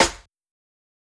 Snares
SNARE_BREAKBREAD.wav